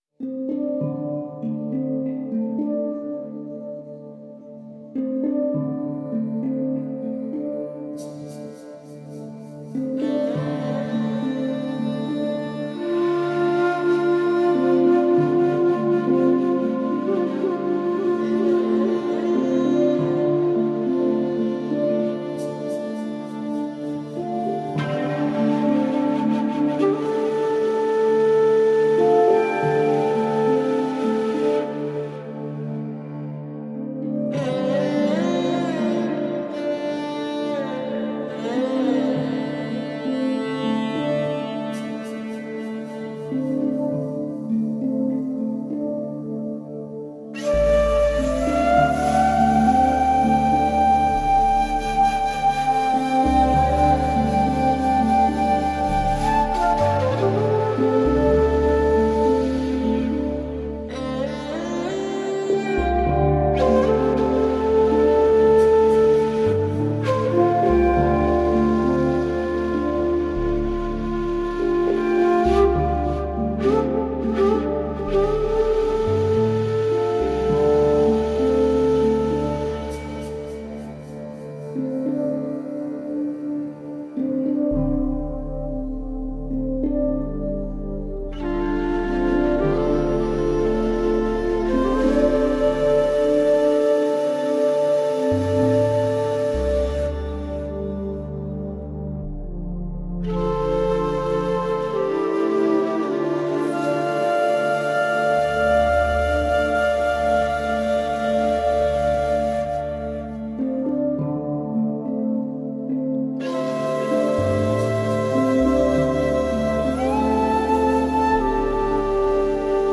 World flute with a serene energy.